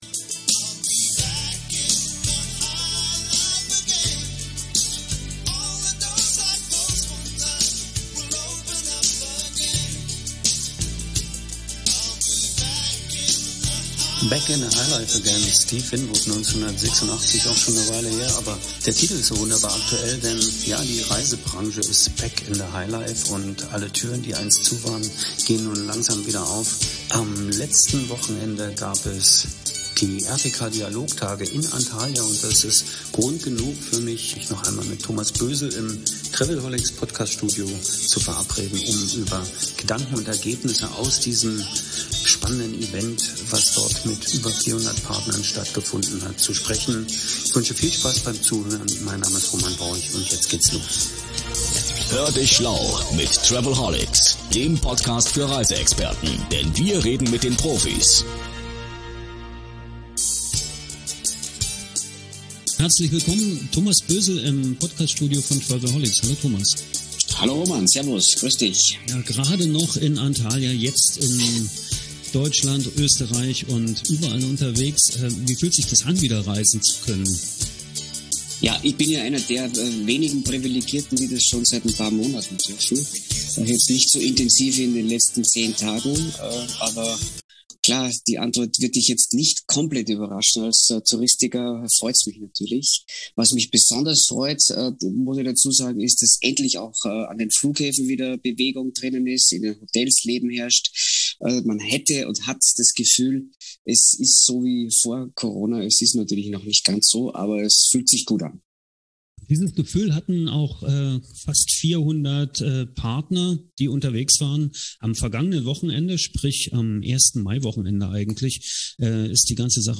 Im "Podcast der Touristik" gibt es einen Rückblick mit Ausblick.